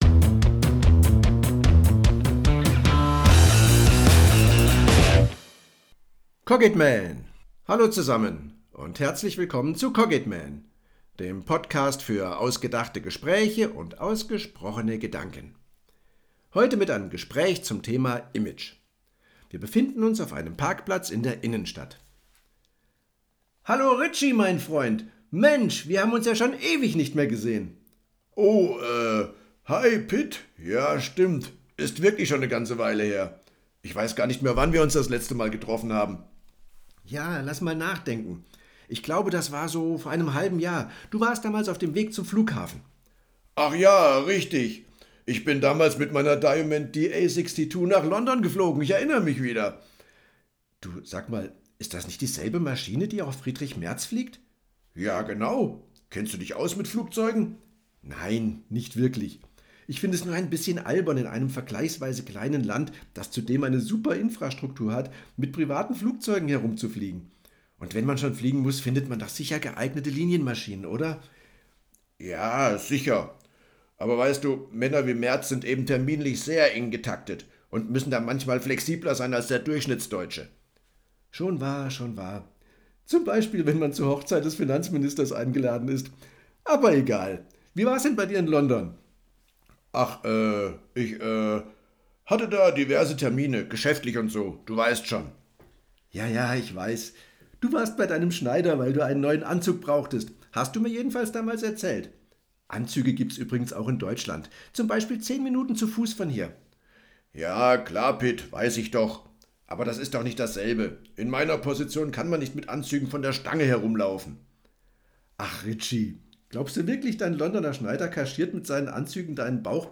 Dialog_Image.mp3